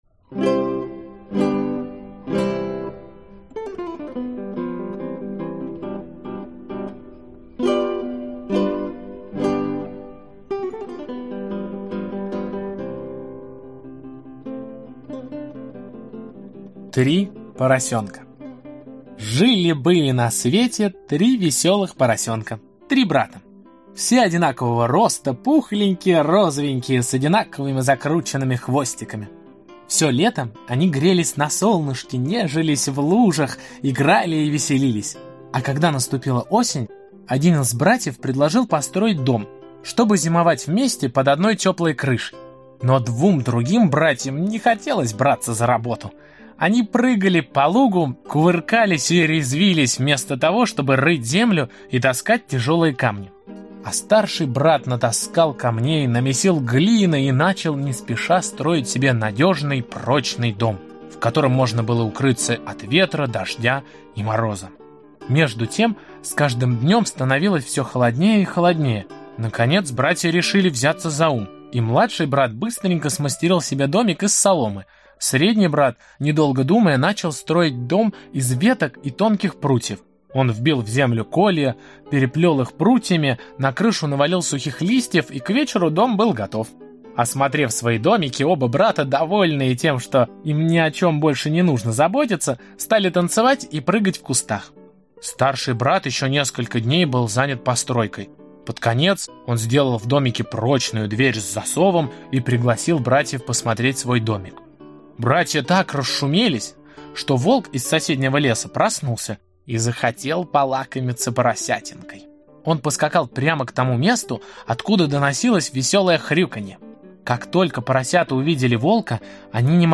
Аудиокнига Три поросенка | Библиотека аудиокниг